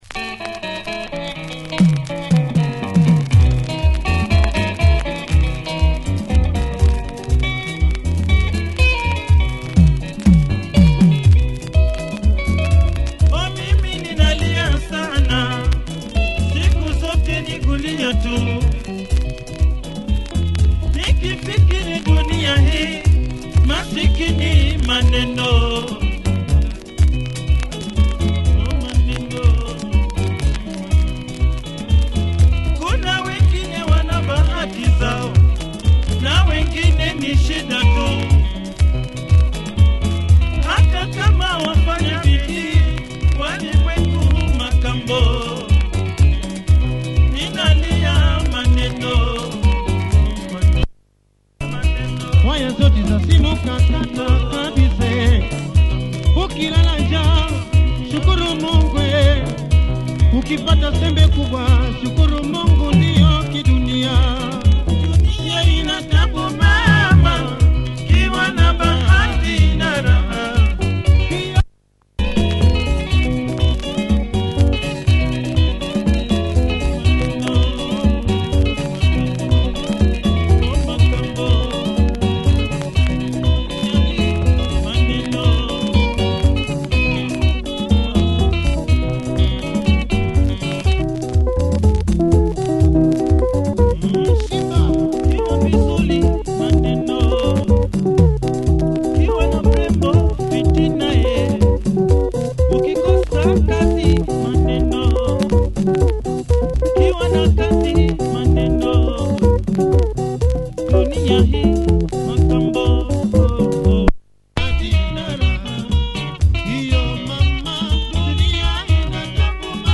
Boom Boom! Synth bass as entered the Kenyan music industry.